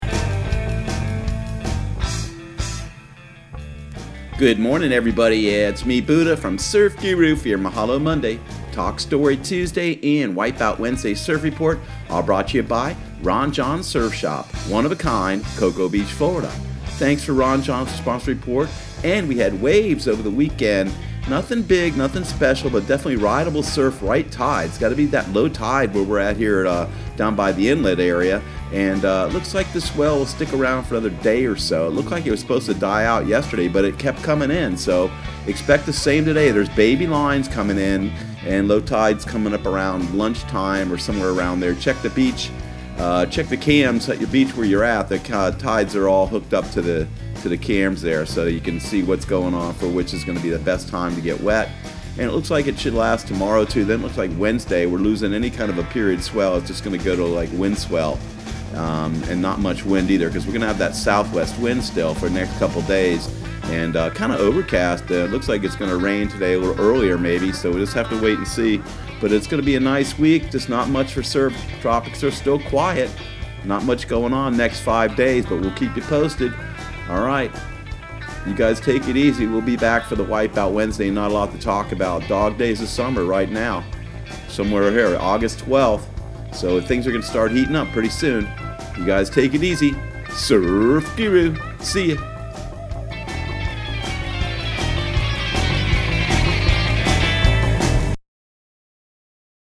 Surf Guru Surf Report and Forecast 08/12/2019 Audio surf report and surf forecast on August 12 for Central Florida and the Southeast.